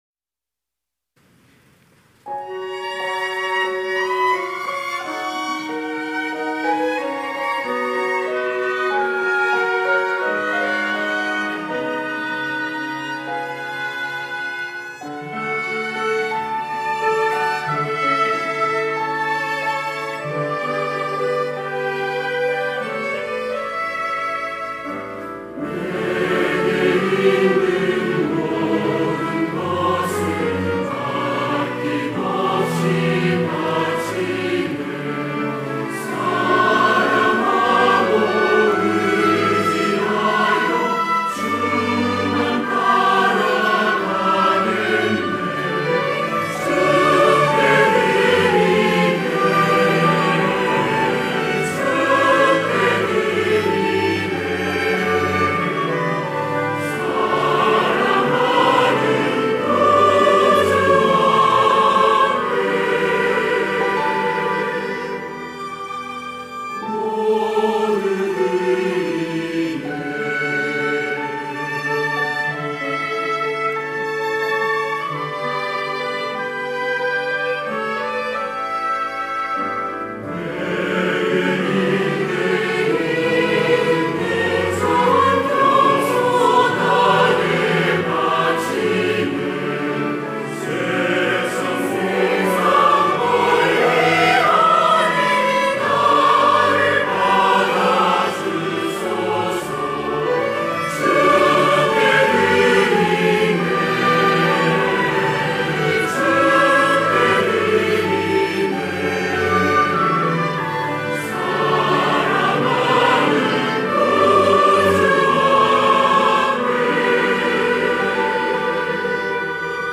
할렐루야(주일2부) - 내게 있는 모든 것
찬양대